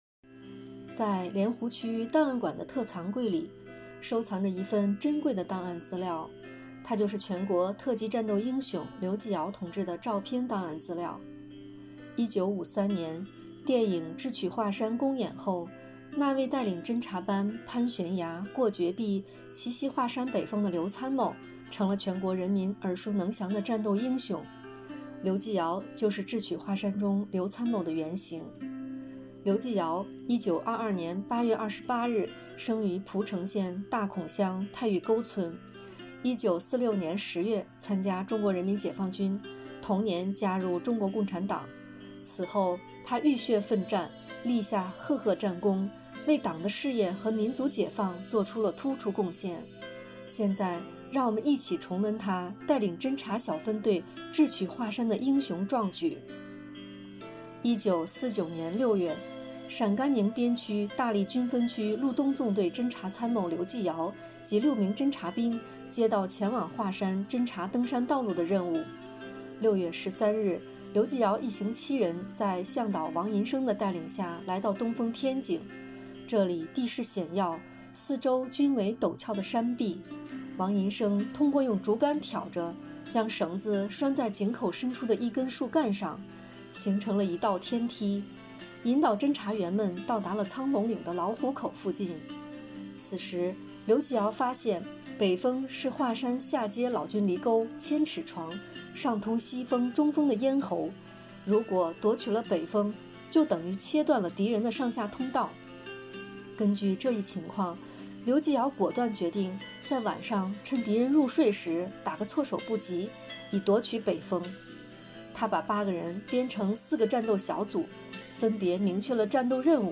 【红色档案诵读展播】全国特级战斗英雄刘吉尧